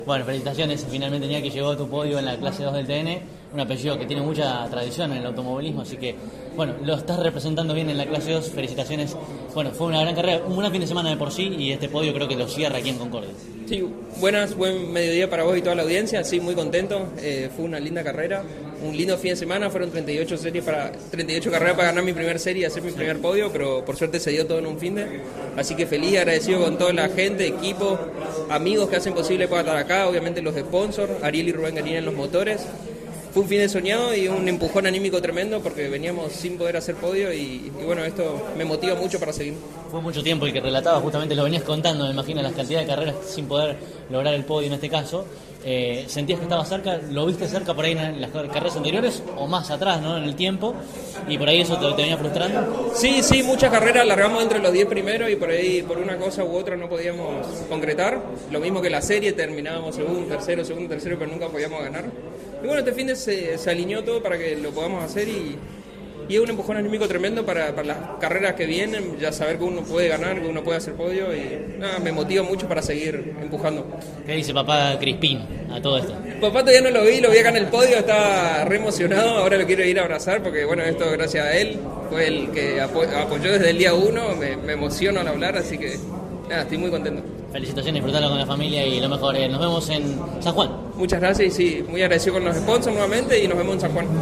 CÓRDOBA COMPETICIÓN estuvo presente en el evento y, al término de la competencia definitiva de la divisional menor, dialogó con cada uno de los protagonistas del podio, así como también del cordobés mejor ubicado al término de la prueba.